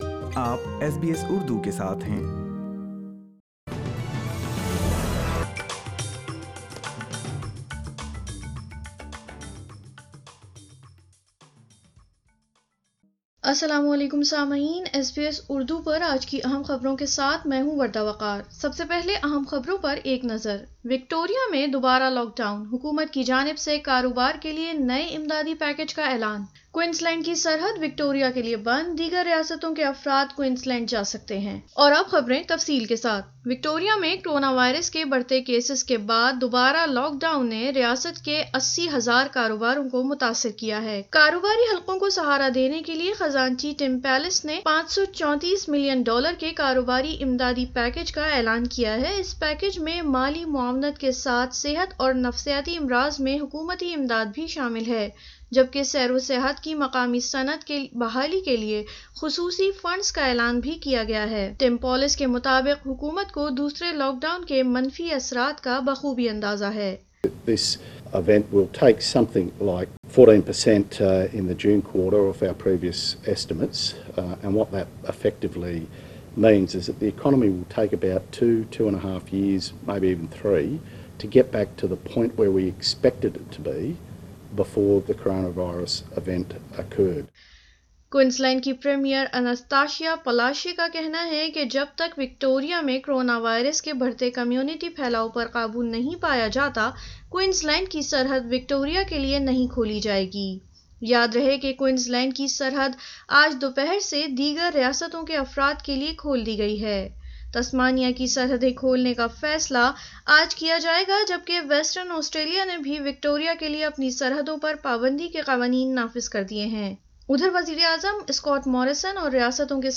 اردو خبریں 10 جولائی 2020